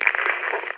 talk.ogg